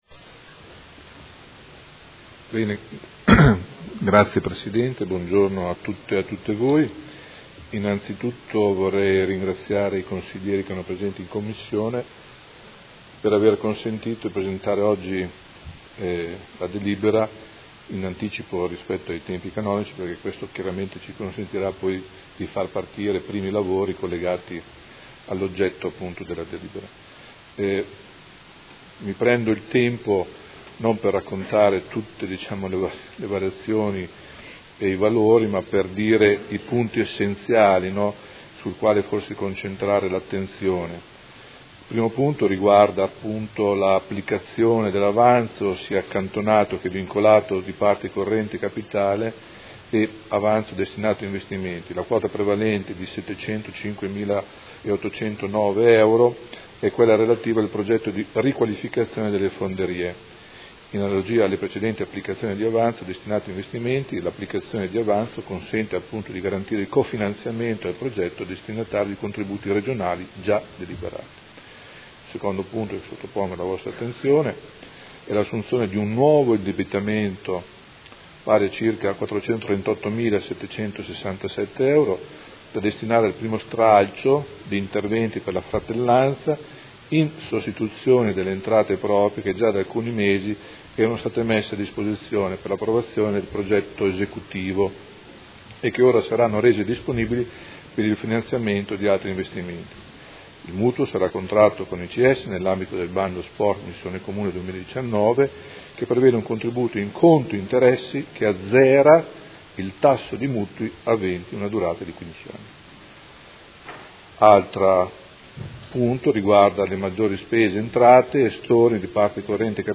Gianpietro Cavazza — Sito Audio Consiglio Comunale